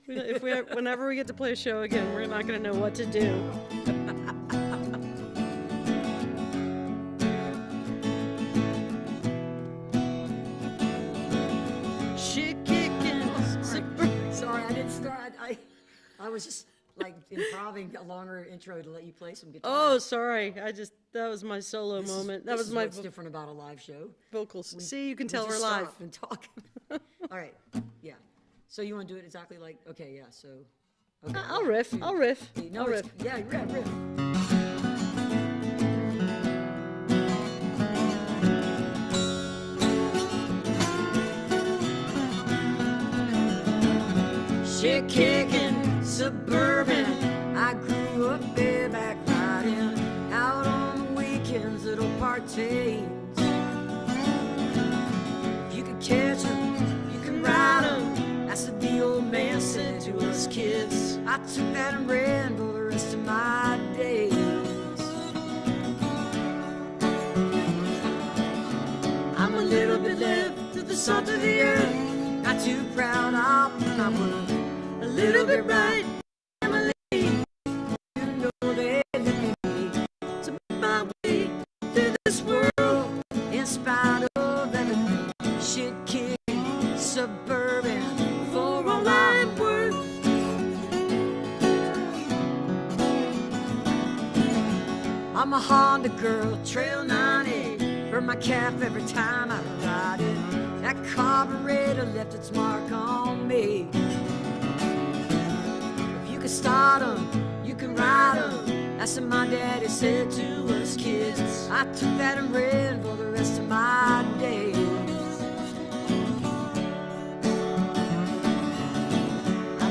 (captured from the youtube livestream)